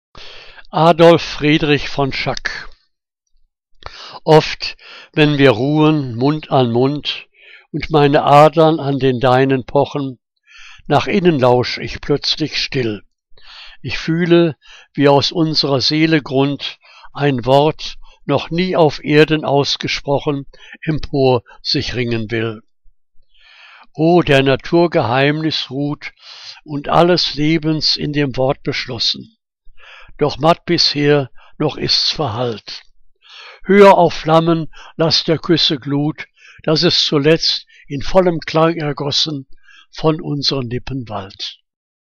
Liebeslyrik deutscher Dichter und Dichterinnen - gesprochen (Adolf Friedrich von Schack)